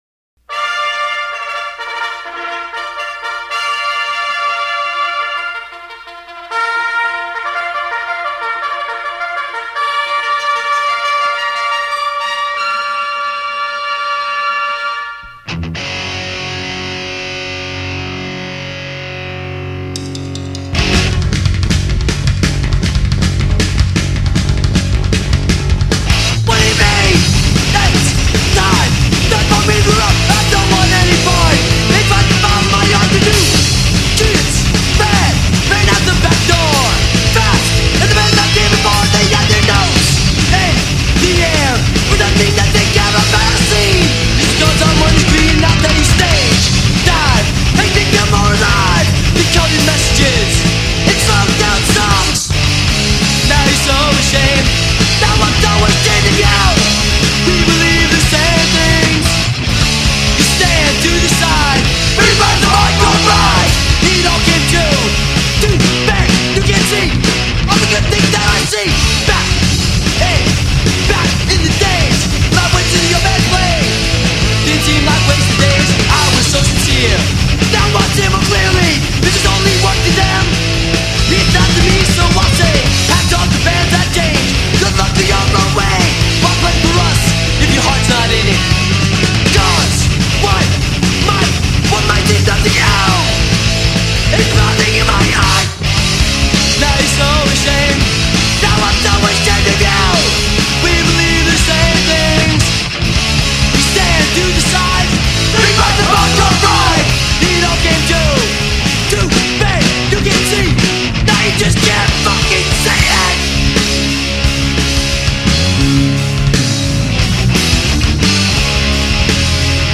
A tasty Hardcore podcast